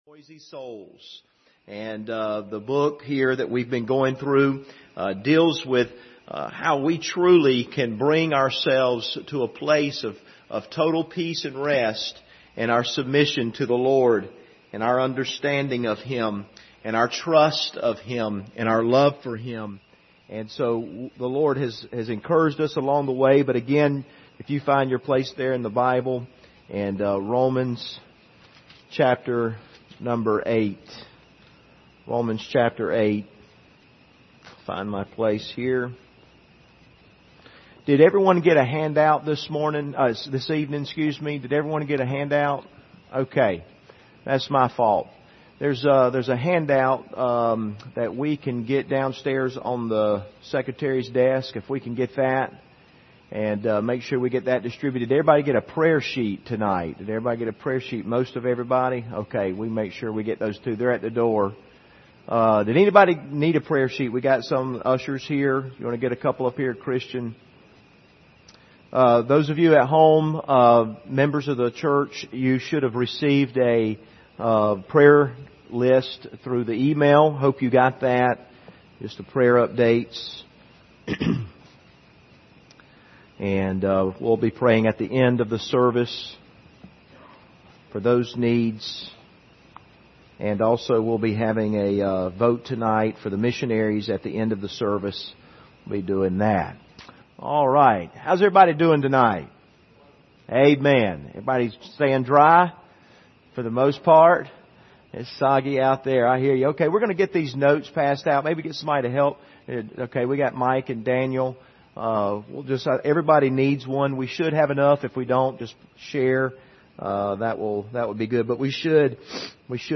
Service Type: Wednesday Evening Topics: Love of God